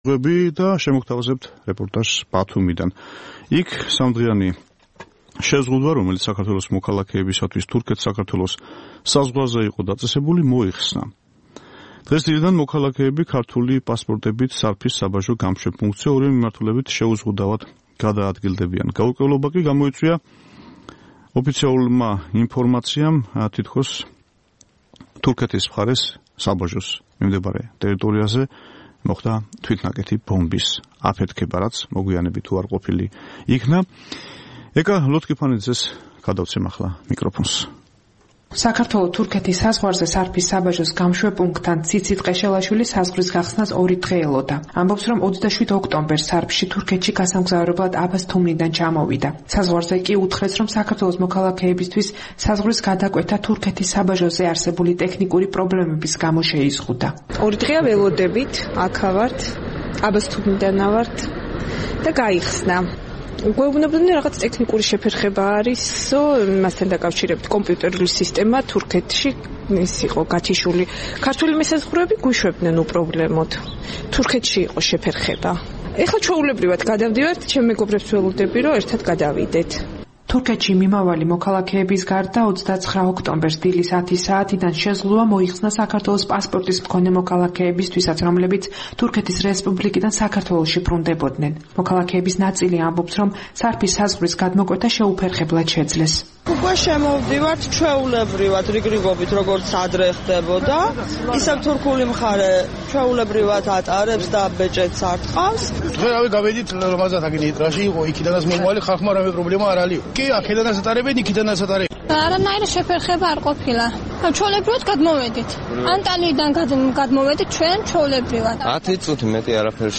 სამდღიანი შეზღუდვა, რომელიც საქართველოს მოქალაქეებისთვის თურქეთ- საქართველოს საზღვარზე იყო დაწესებული, მოიხსნა. დღეს, დილიდან, მოქალაქეები ქართული პასპორტებით სარფის საბაჟო გამშვებ პუნქტზე ორივე მიმართულებით შეუზღუდავად გადაადგილდებიან. ოფიციალური ინფორმაციით, საქართველოს მოქალაქეებს საზღვრის გადაკვეთა შეეზღუდათ ტექნიკური პრობლემების გამო, რომელიც თურქეთის საბაჟოზე სამი დღის წინ შეიქმნა. ამიტომ, საზღვრის ორივე მხარეს სამი დღის განმავლობაში მოქალქეთა რიგები იდგა. გთავაზობთ რეპორტაჟს სარფის საბაჟოდან.